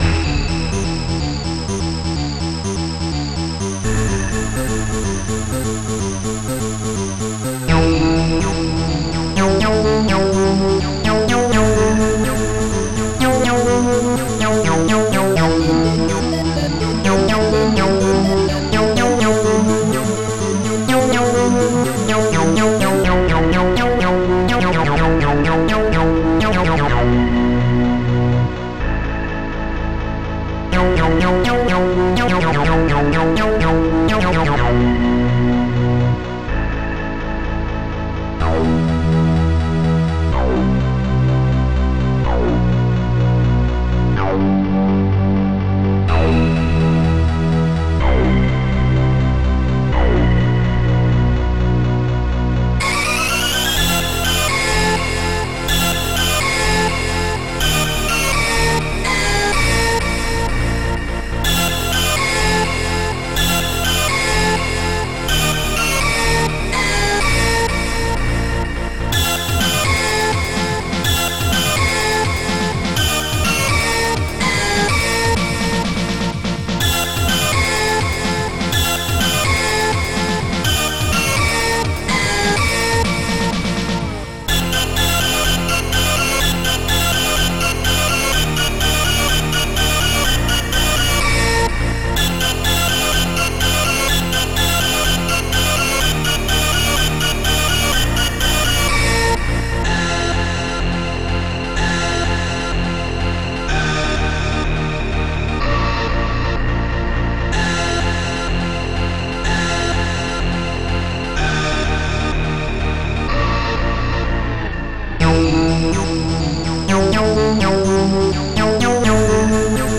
Protracker and family
st-01:leadsynth